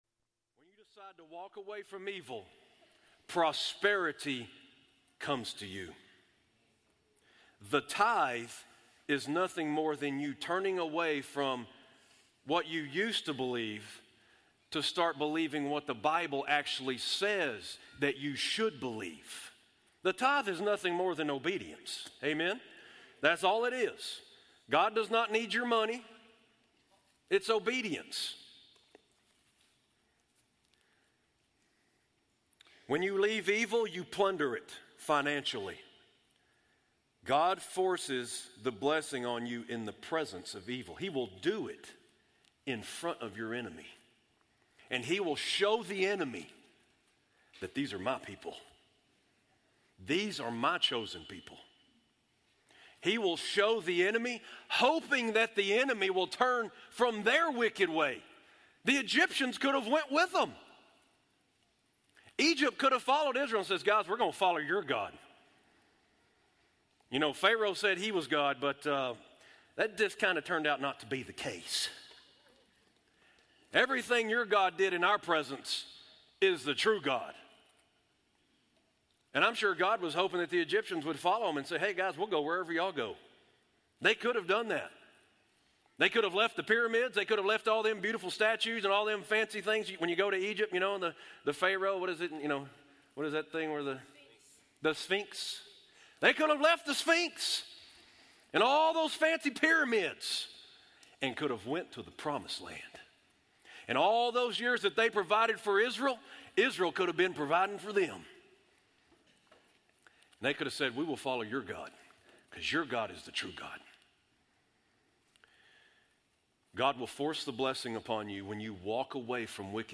Live Stream Our Complete Service Sunday mornings at 10:30am Subscribe to our YouTube Channel to watch live stream or past Sermons Listen to audio versions of Sunday Sermons